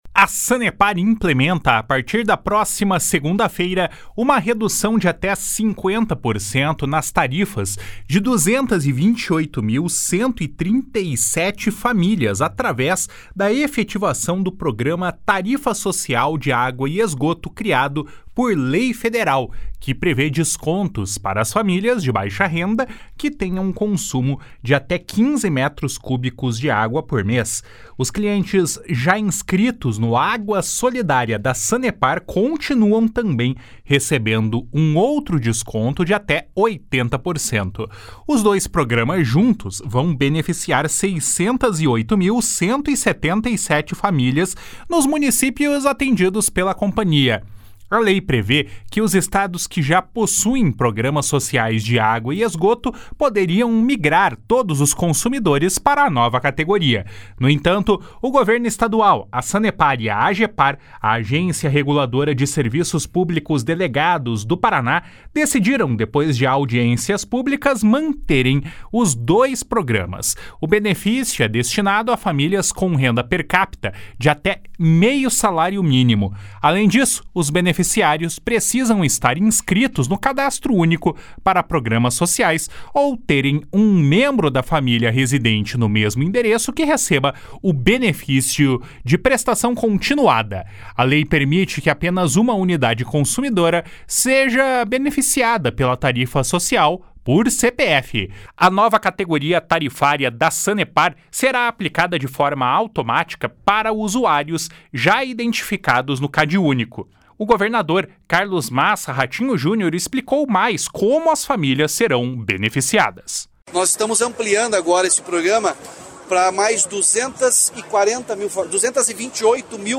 O governador Carlos Massa Ratinho Junior explicou mais como as famílias serão beneficiadas. // SONORA RATINHO JUNIOR //